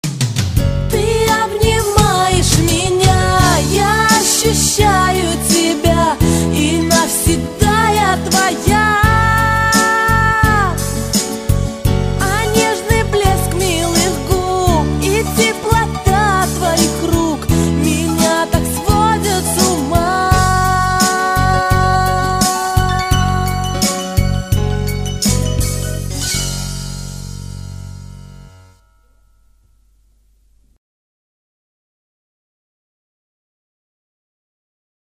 • Качество: 128, Stereo
пианино
ретро
Рингтончик из старой доброй романтической песенки, медлячок!